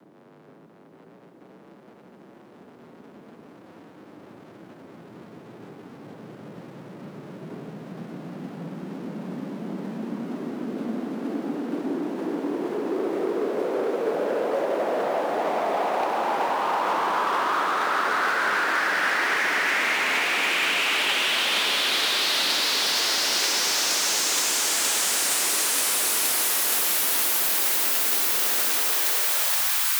VUF1 Clean Noise Ramps 128BPM 140BPM